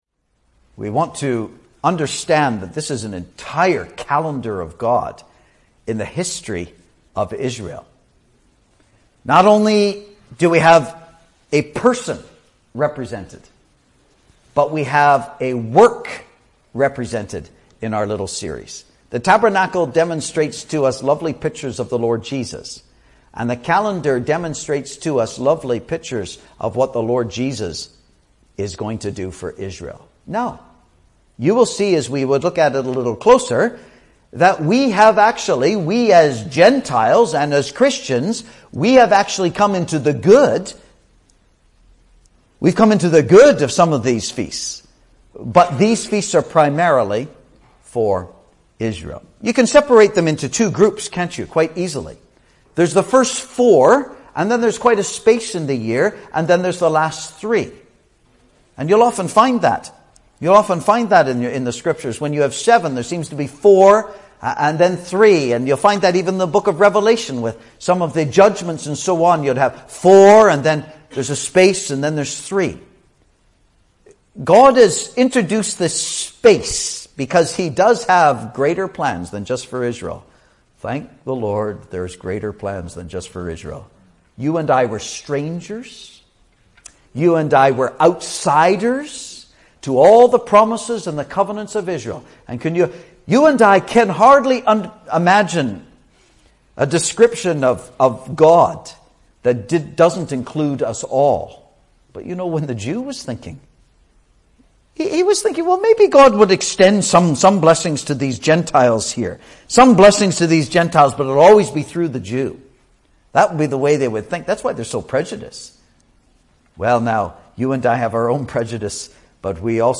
He expounds the spiritual significance of the passover, the feast of unleavened bread, firstfruits, Pentecost, trumpets, atonement and tabernacles (Message preached 11th Feb 2018)